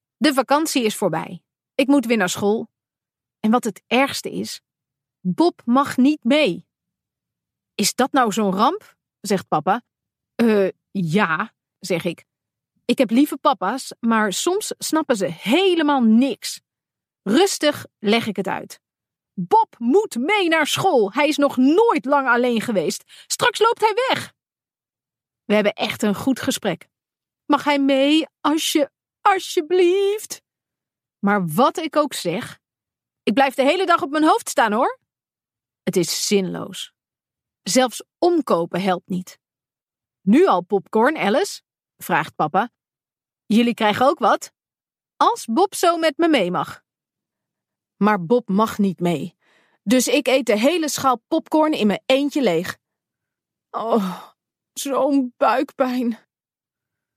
Ook van het vierde deel: Bob Popcorn Meesterkok staat het luisterboek klaar!